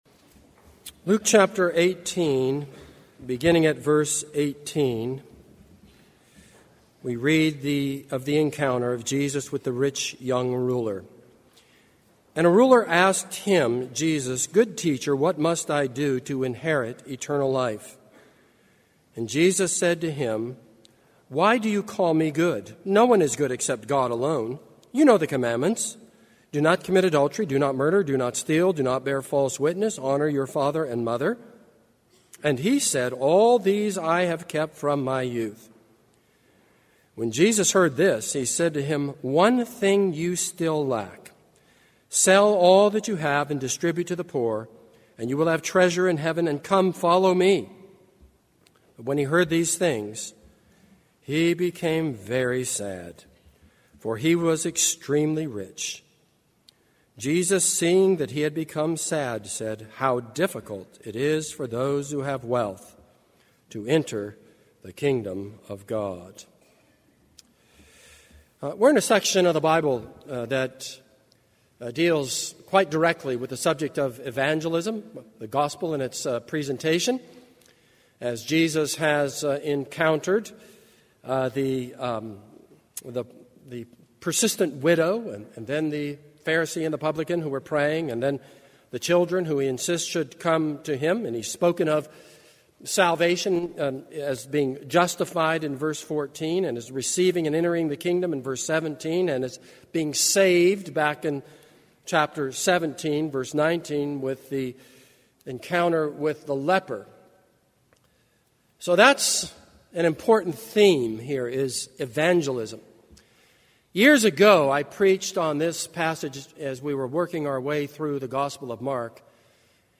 This is a sermon on Luke 18:18-27.